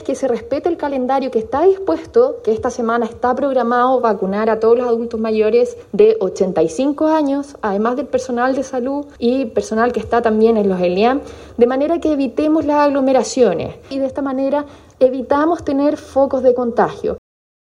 La seremi de Salud de la región Metropolitana, Paula Labra, llamó a cumplir con el calendario, para evitar aglomeraciones y así no tener focos de contagios.